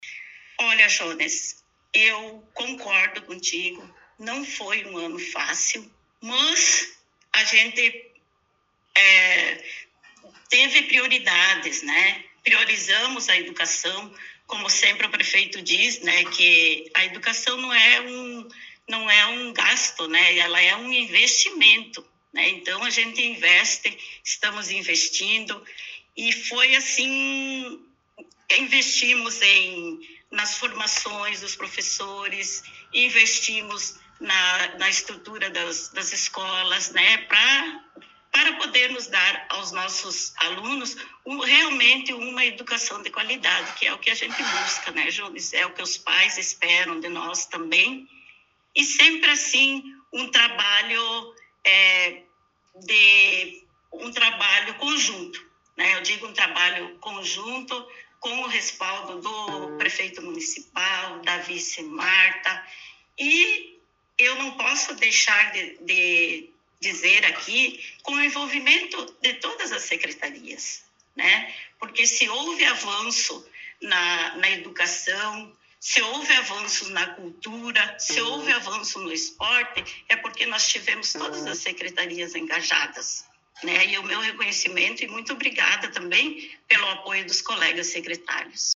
Secretária Municipal de Educação, Jorgina de Quadros, concedeu entrevista